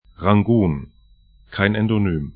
Pronunciation
Yangon   raŋ'gu:n